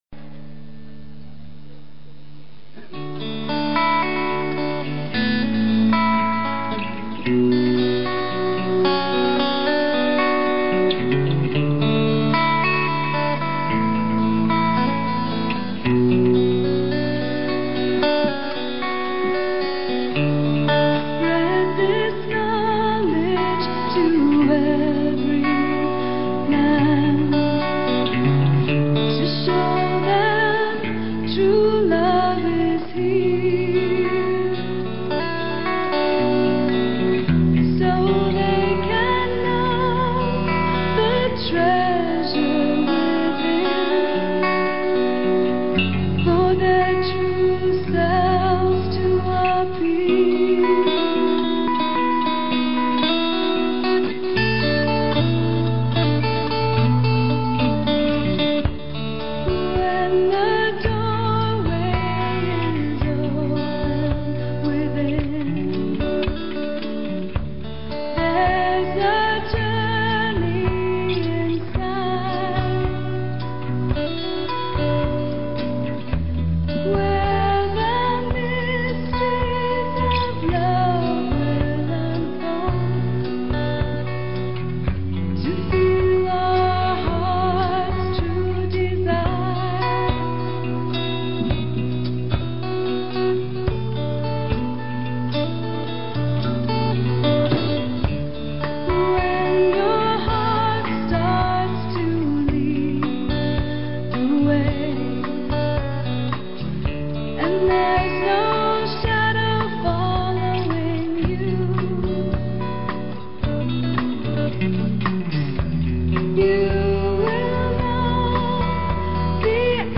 This recording of a live version of